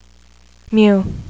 Mu (Mew)